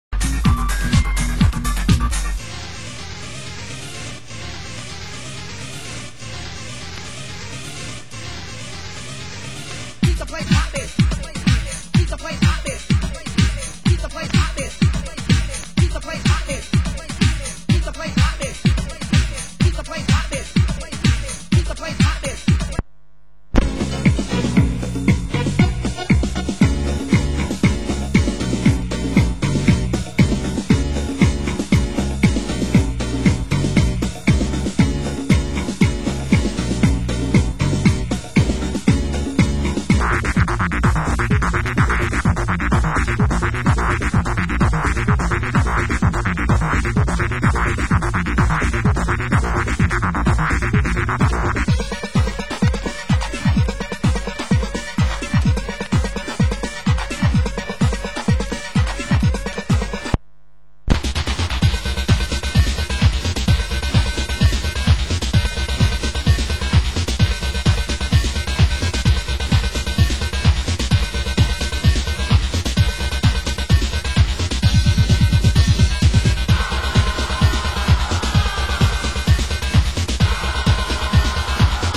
Genre: US Techno